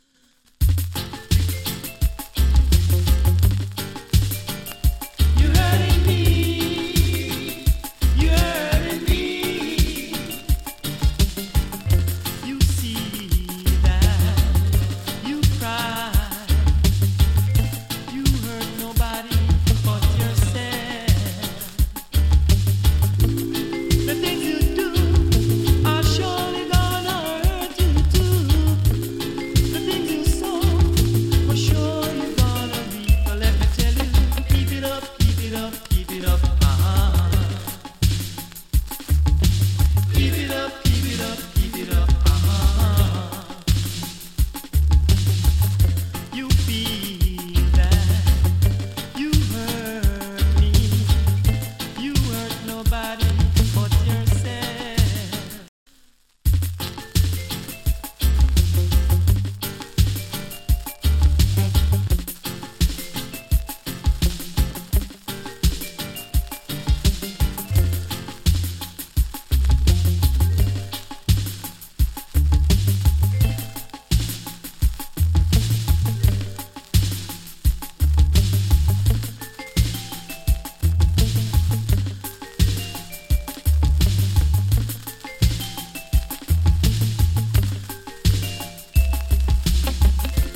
DANCE HALL 90'S
チリ、ジリノイズ少し有り。